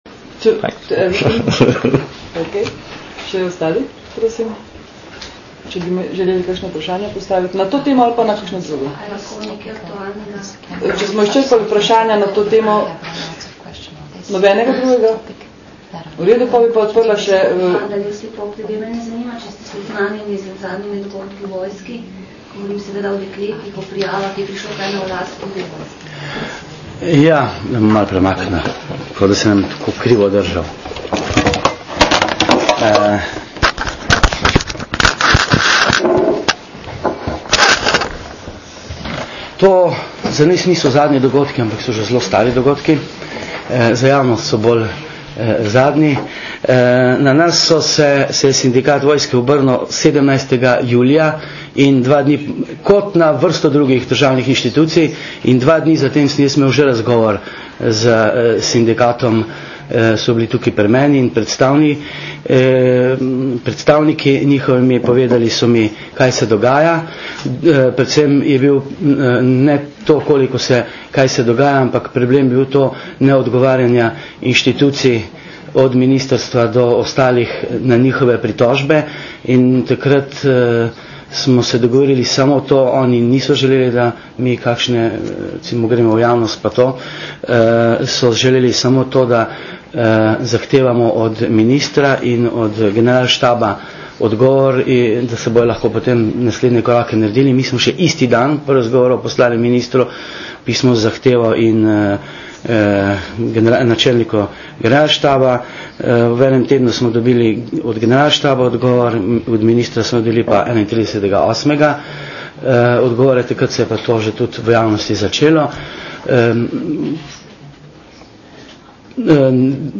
Varuh človekovih pravic na novinarski konferenci o učinkih besed in aktualnih temah
Varuh človekovih pravic Matjaž Hanžek je odgovarjal še na vprašanja novinarjev o aktualnih temah.